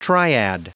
Prononciation du mot triad en anglais (fichier audio)
Prononciation du mot : triad